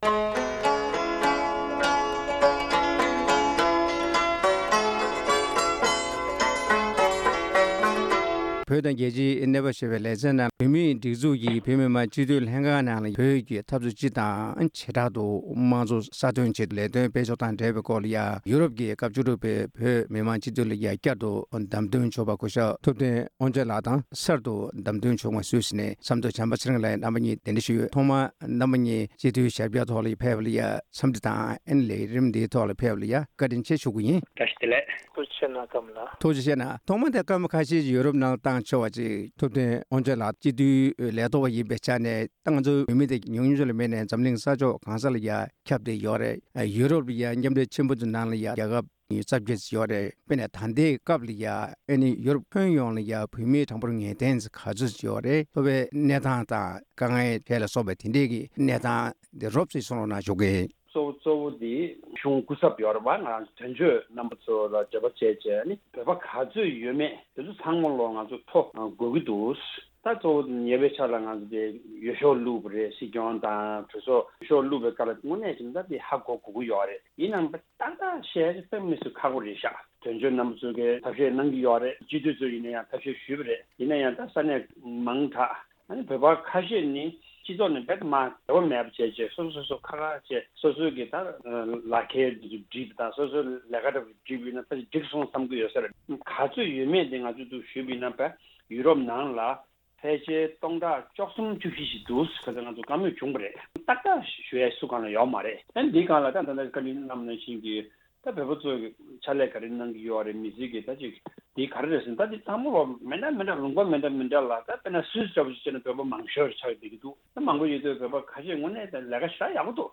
བོད་མིའི་མང་གཙོ་དང་བོད་ཀྱི་འཐབ་རྩོད་རྩོད་ལེན་དང་འབྲེལ་བའི་སྐོར་གླེང་མོལ་ཞུས་པ།